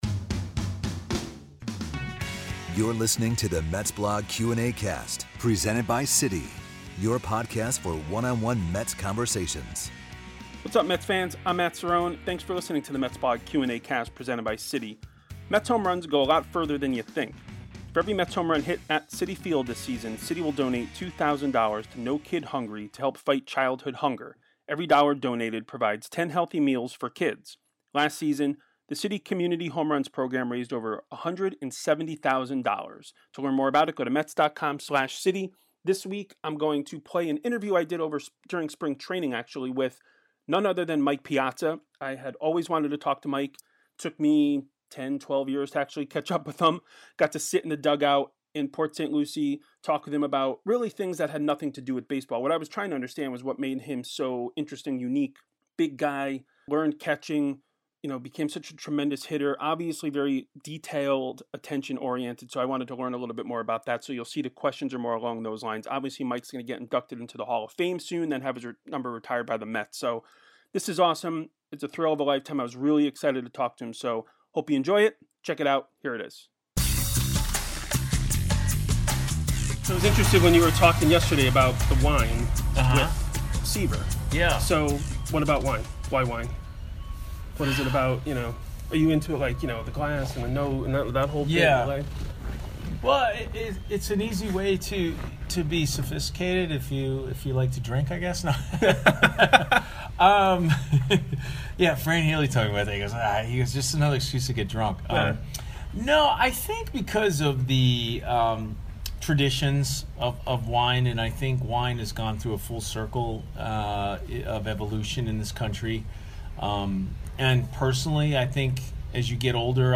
It took 12 years, but he finally caught up with Mike in the St. Lucie dugout during Spring Training, where he talked about his love of wine, soccer, what made him a successful catcher, and the importance of hard work and focusing on the details in life and in baseball.